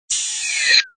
SwooshOpen.aac